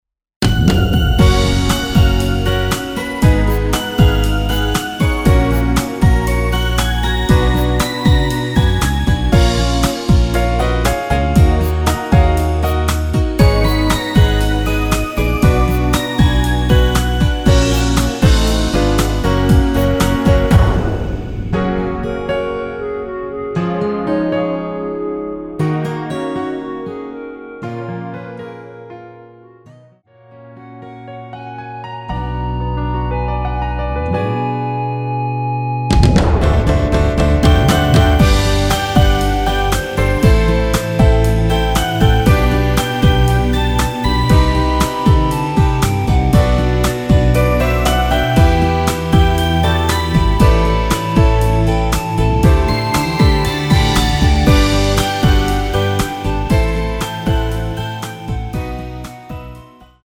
원키에서(+3)올린 멜로디 포함된 MR 입니다.(미리듣기 참조)
F#
앞부분30초, 뒷부분30초씩 편집해서 올려 드리고 있습니다.
중간에 음이 끈어지고 다시 나오는 이유는